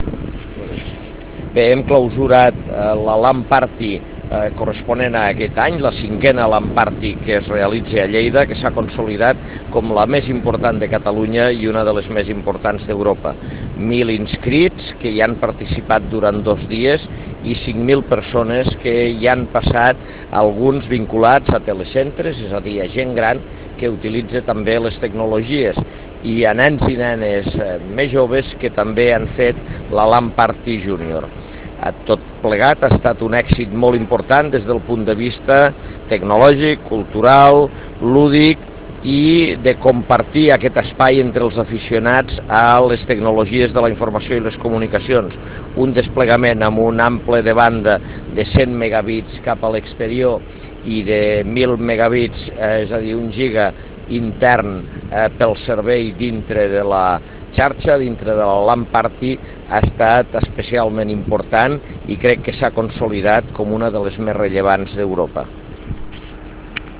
arxiu-de-veu-on-ros-fa-balanc-de-la-5a-lan-party-de-lleida